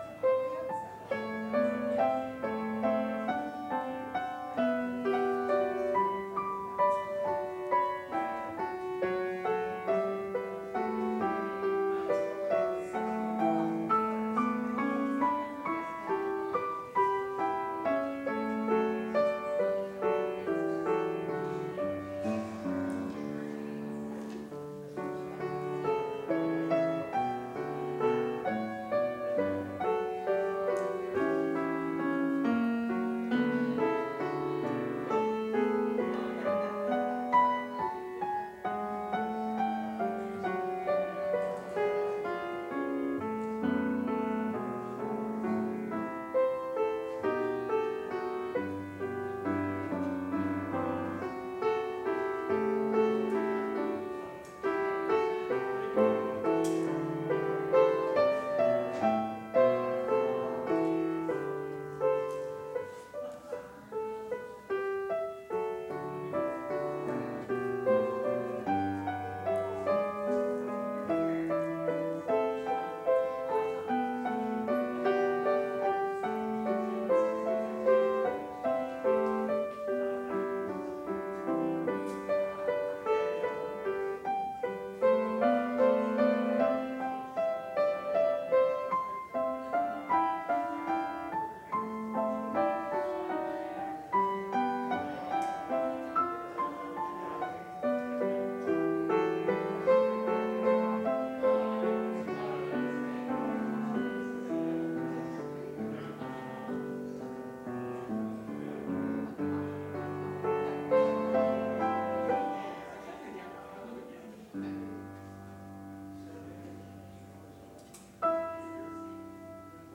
Full worship service: Aug 15, 2021 (Word to the Wise)
We'll also have live music, time for kids and prayer.